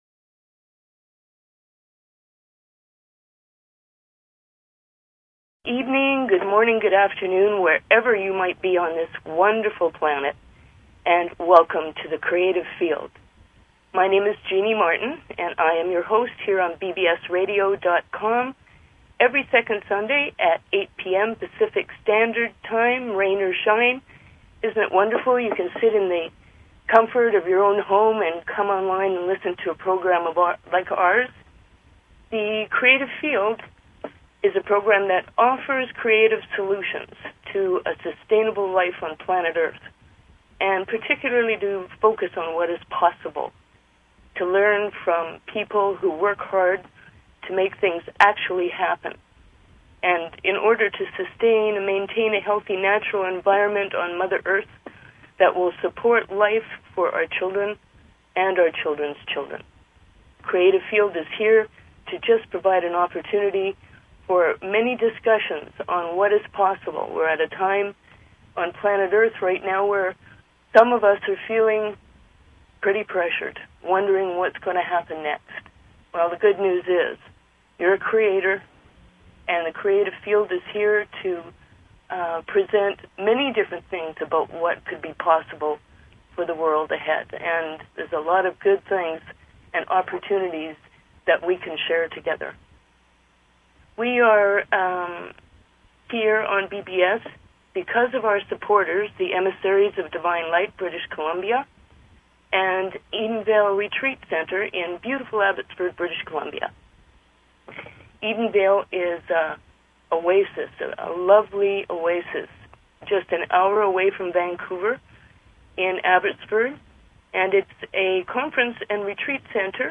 Talk Show Episode, Audio Podcast, The_Creative_Field and Courtesy of BBS Radio on , show guests , about , categorized as